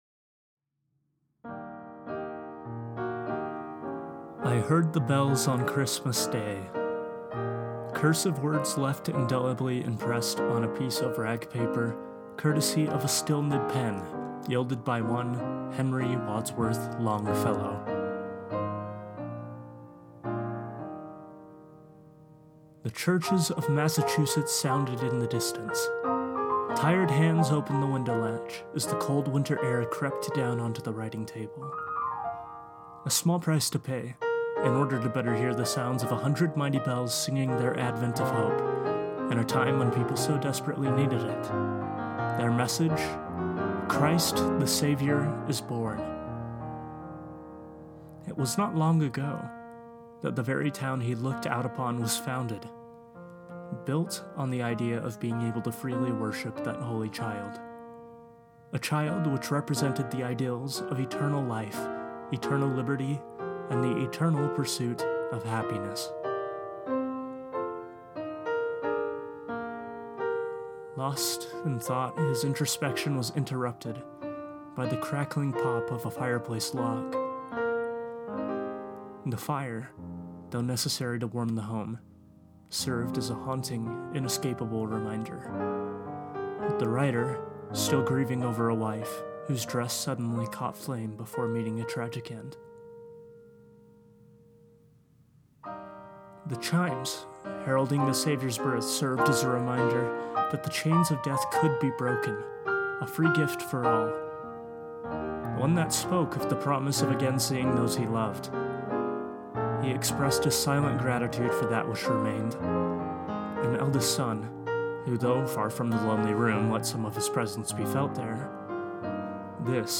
for piano and a narrator (male or female)